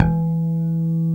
Index of /90_sSampleCDs/Roland - Rhythm Section/BS _Jazz Bass/BS _E.Bass FX